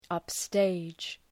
Shkrimi fonetik {,ʌp’steıdʒ}